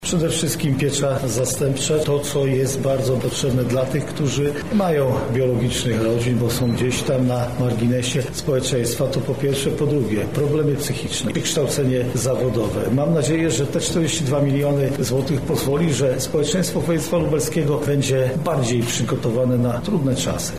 O kierunkach realizacji projektów dotacyjnych mówi Marszałek Województwa Lubelskiego Jarosław Stawiarski: